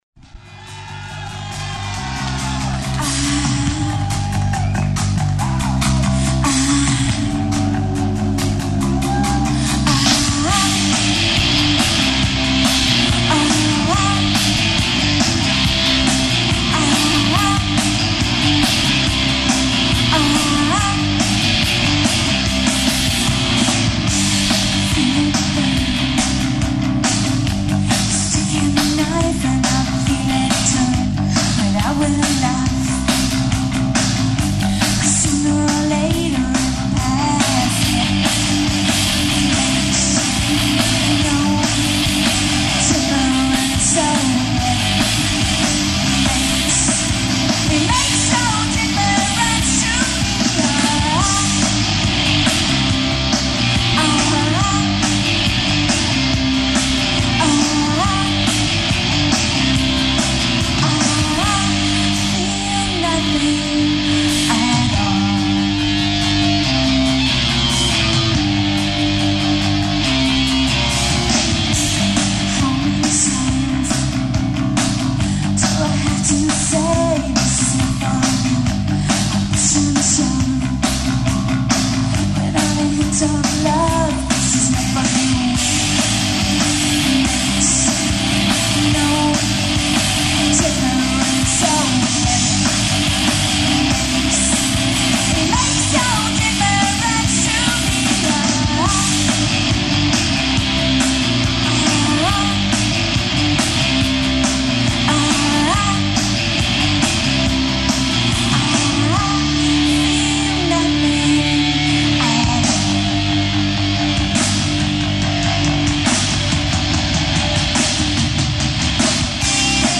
Britpop
This track was recorded live in Chicago in December of 1992.